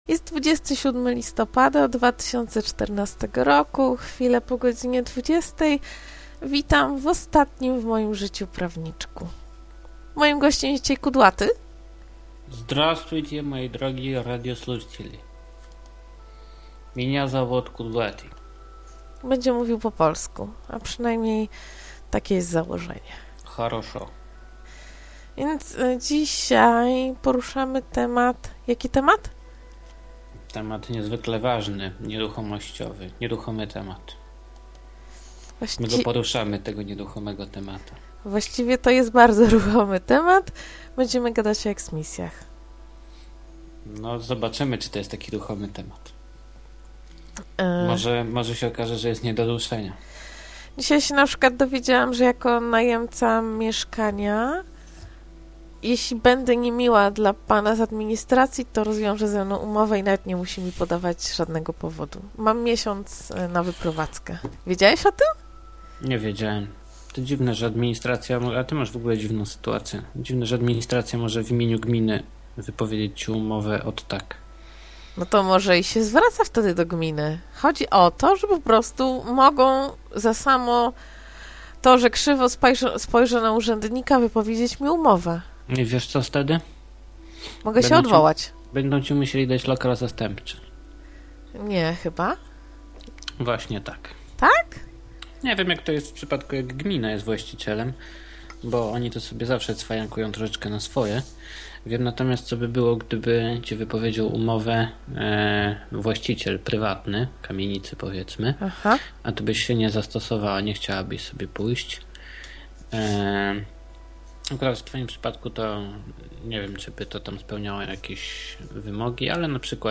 Miało być o eksmisji, ale było tyle ciekawych innych tematów, że na tym nie poprzestaliśmy. Dużo ciekawych telefonów i dyskusji o prawach w różnych krajach. O tym, co może policjant i zwykły obywatel.